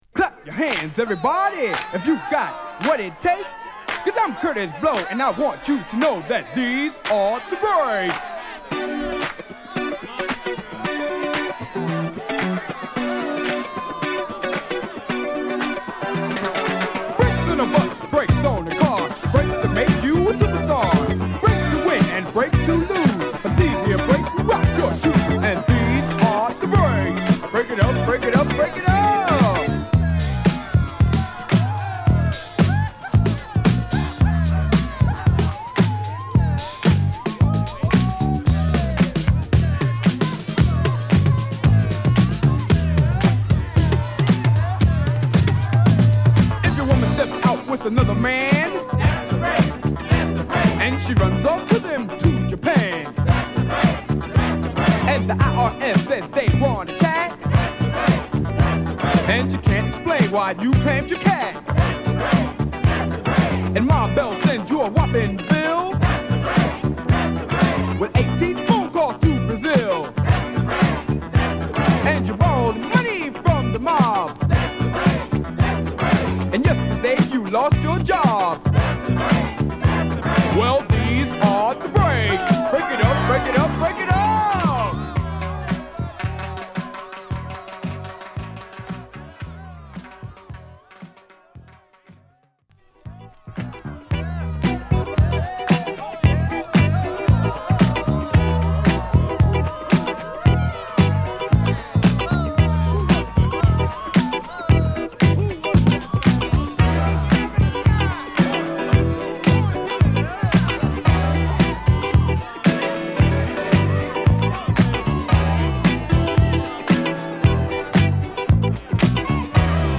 (Vocal)
(Instrumental)